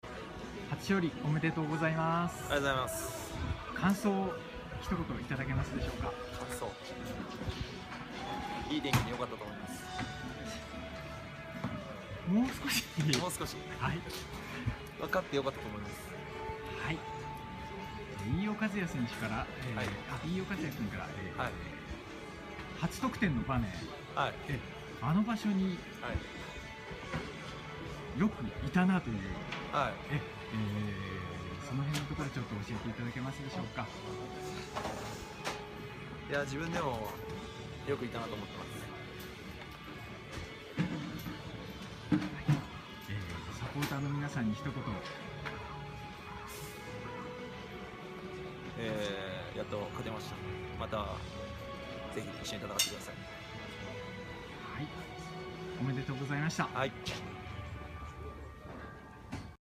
インタビュー